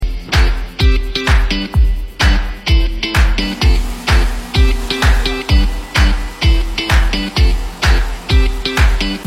600W cold spark machine sound effects free download